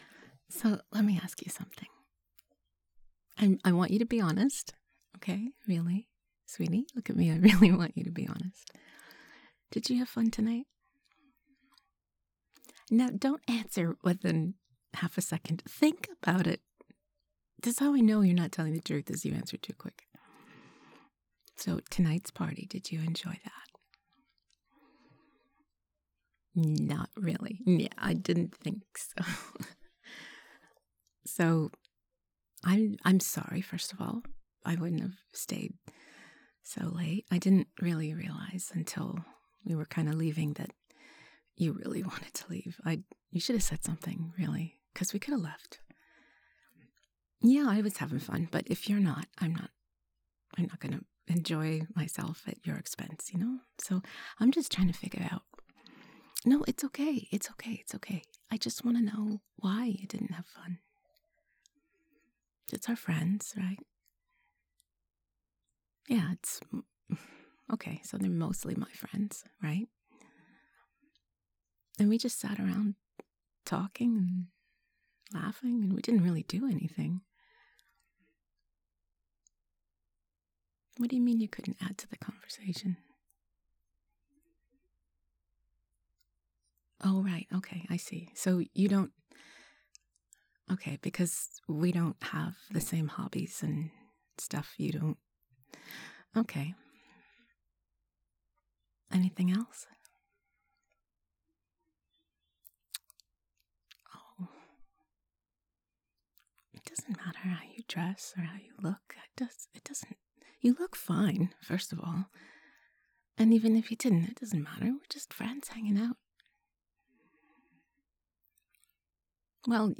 I feel like this type of situation would normally be a perfect starting point for an argument in most relationships but that sweet, understanding with that gentle girlfriend tone...it's amazing as an open-hearted intimate moment!